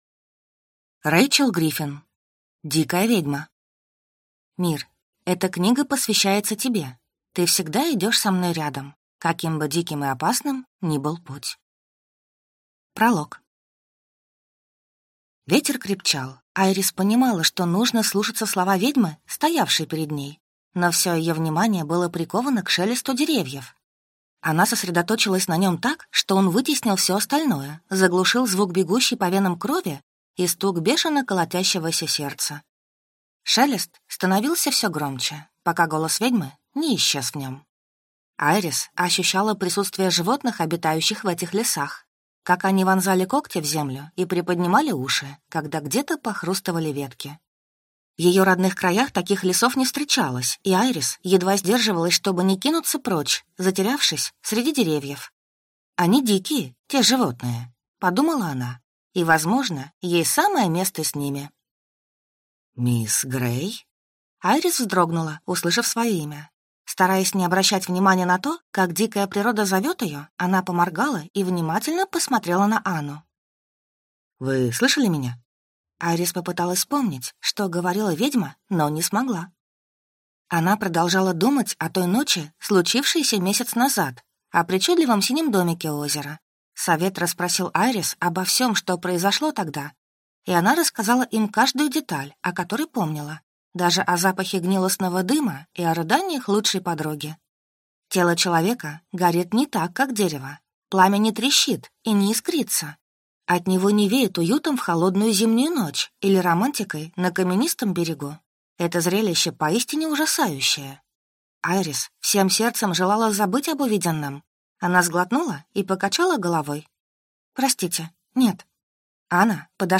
Аудиокнига Дикая ведьма | Библиотека аудиокниг
Прослушать и бесплатно скачать фрагмент аудиокниги